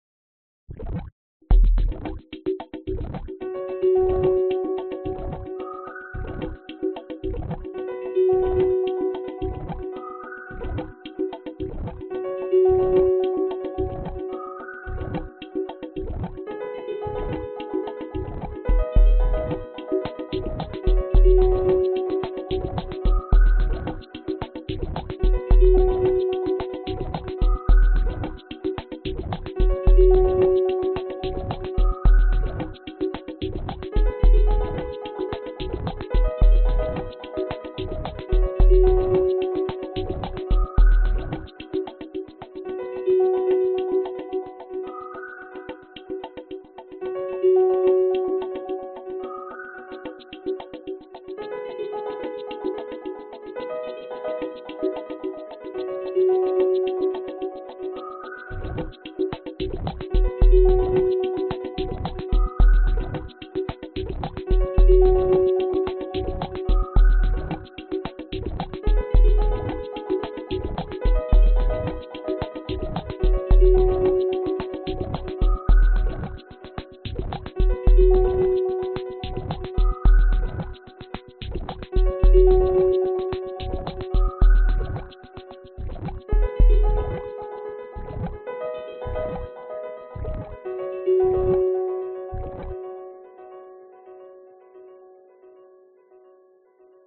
描述：预览所有音轨对齐为零。 110 BPM 4条轨道用于切割。 鼓/弹跳打击乐口哨合成器键
Tag: 寒意 低速 电子 器乐